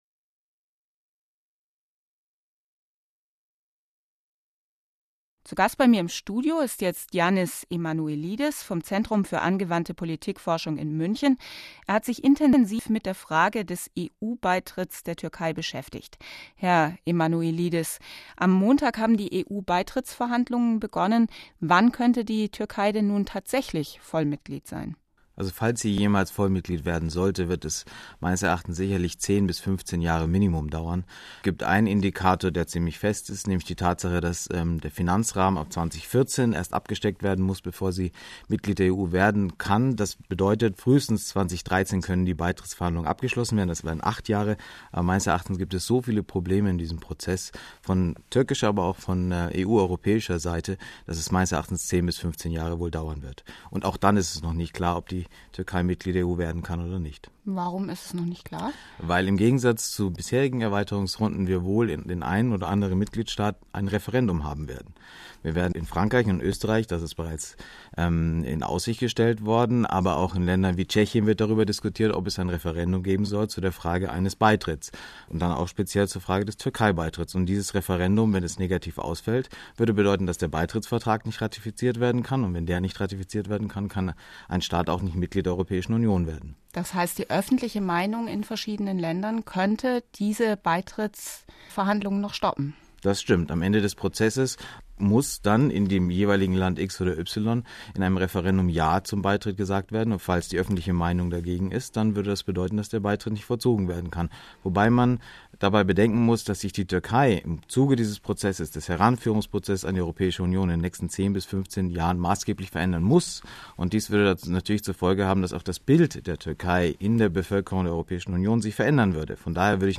BR-Interview
zum Beginn der Beitrittsverhandlungen mit der Türkei am 04.10.2005. Das Interview wurde am 07.10.2005 im Wissensmagazin radioWissen auf Bayern2Radio ausgestrahlt. Thema der Sendung: Der "ungeliebte Kandidat": Die Türkei zwischen Orient und Okzident.